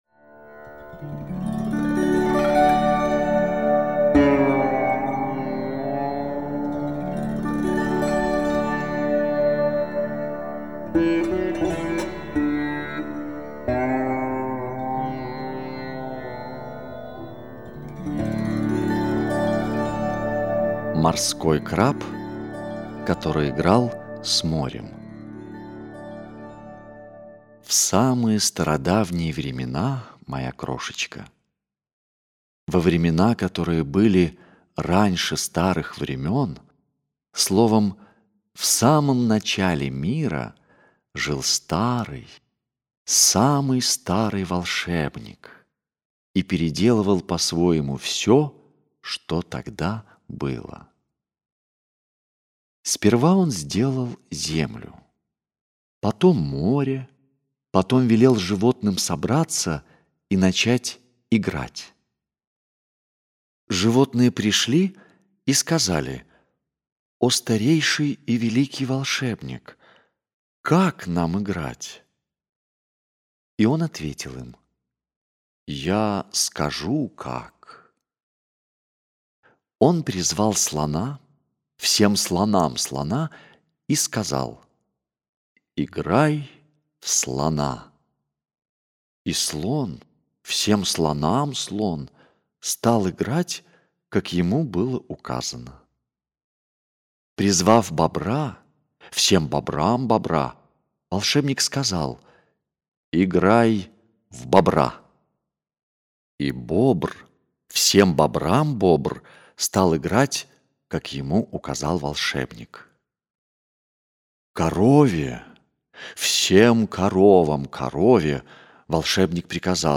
Морской краб, который играл с морем - аудиосказка Киплинга - слушать